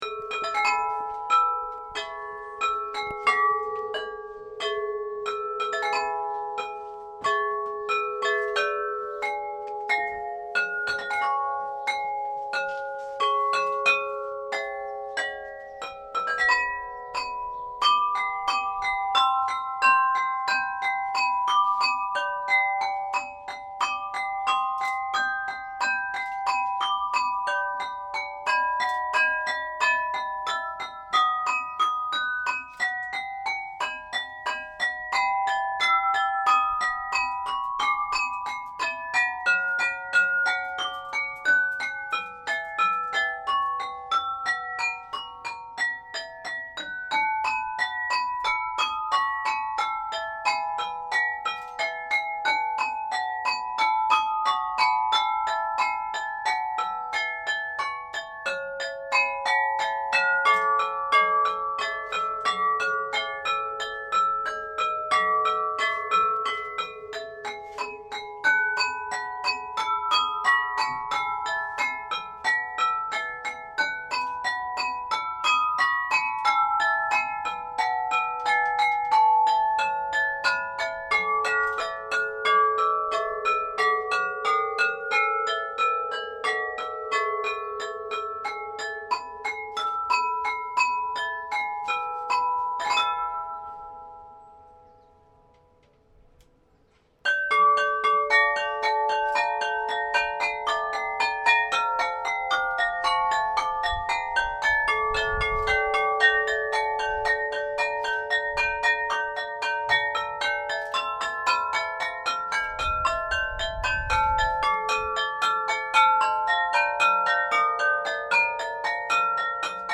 Porcelain_Carillon.mp3